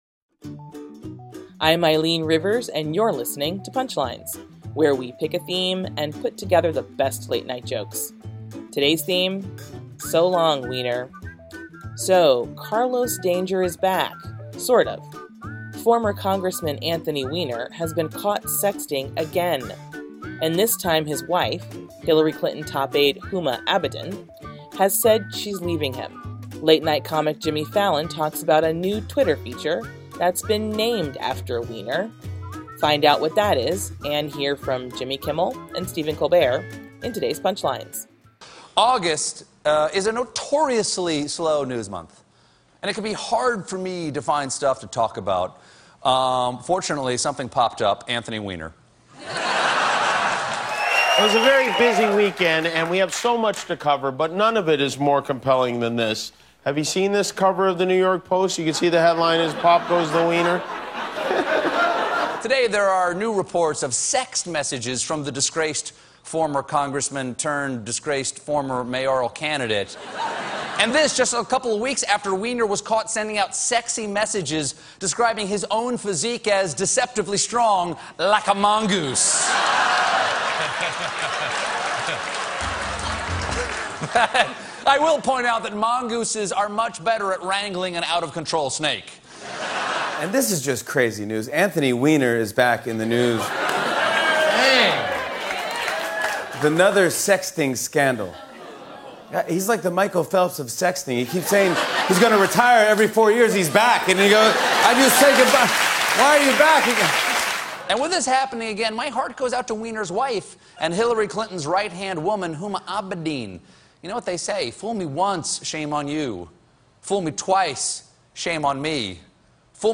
The late-night comics on the former politician who got caught in yet another sexting scandal!